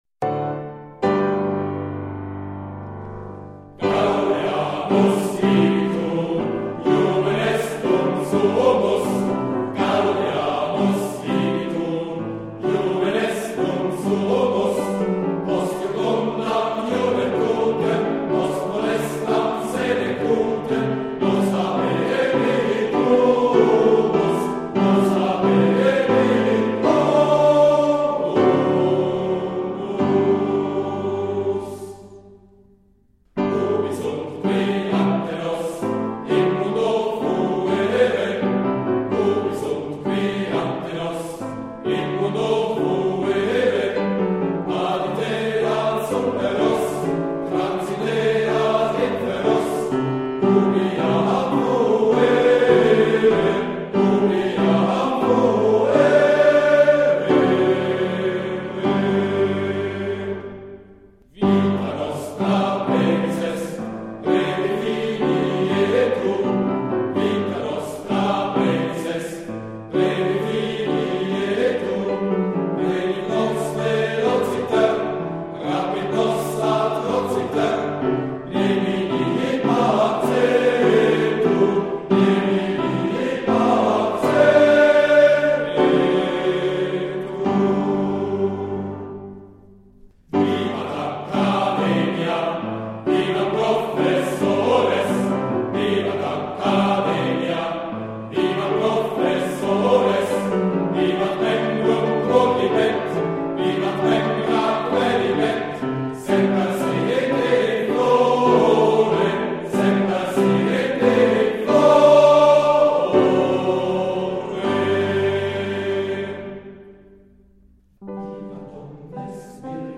Medievale-Himno-Universitario-GaudeamusIgitur.mp3